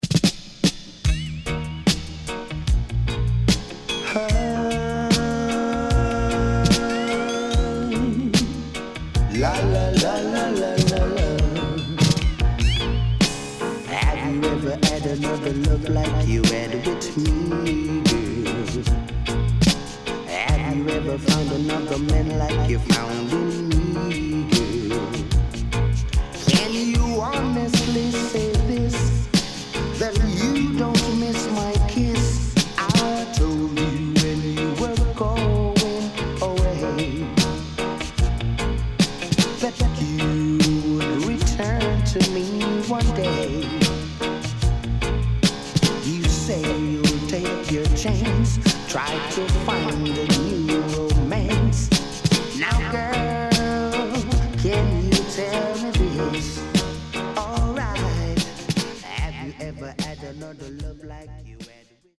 Early 80's Killer One Drop Tune 良曲多数収録